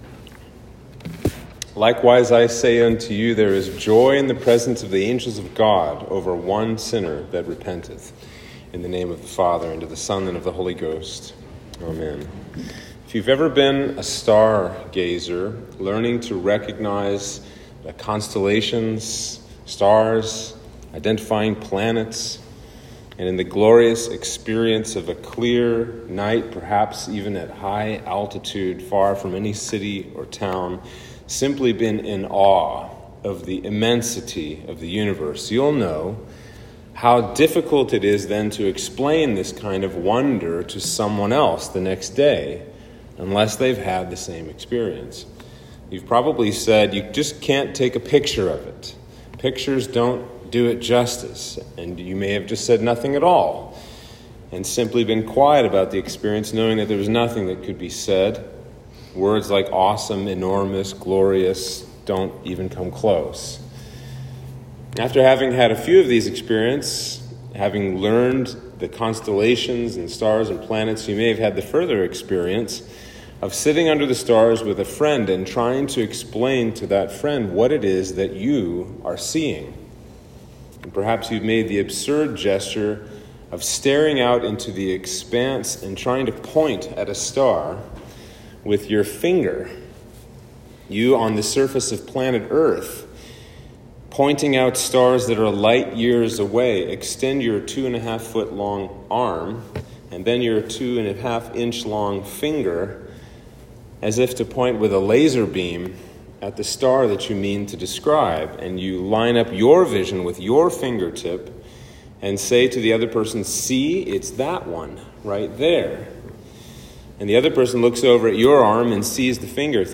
Sermon for Trinity 3